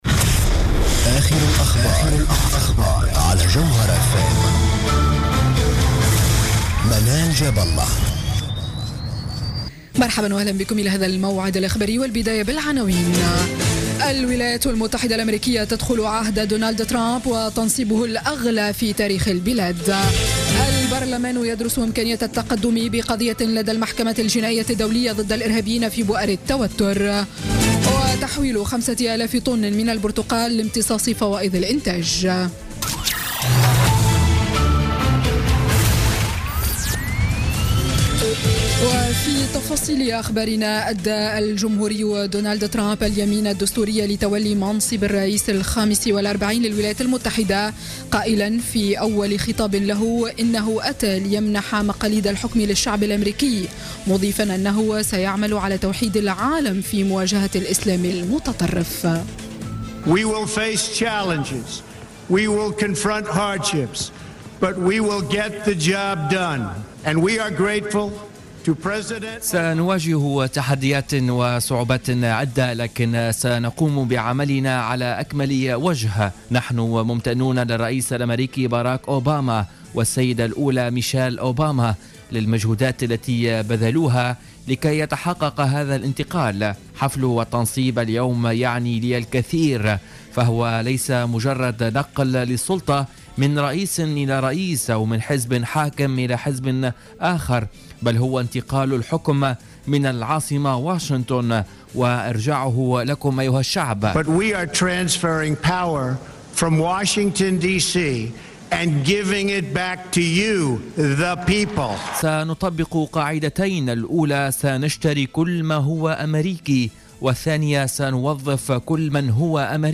Journal Info 19h00 du Vendredi 20 Janvier 2017